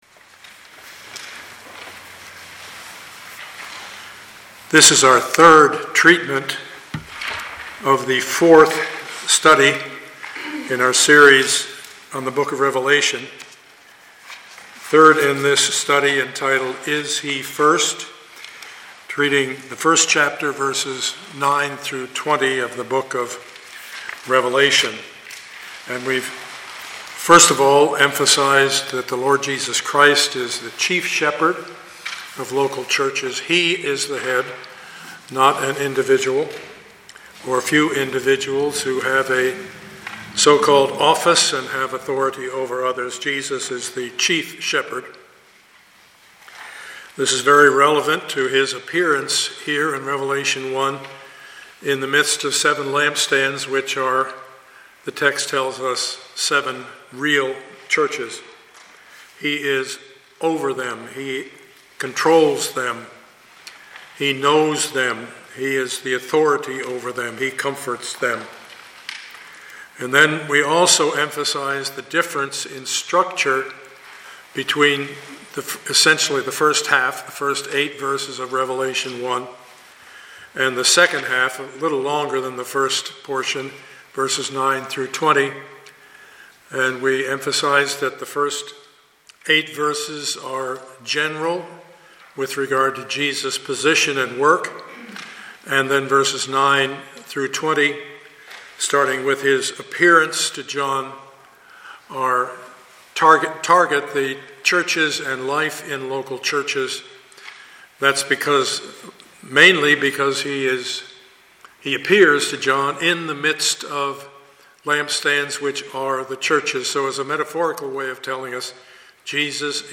Studies in the Book of Revelation Passage: Revelation 1:9-20 Service Type: Sunday morning Part 4C of the Series « Studies in the Book of Revelation #4B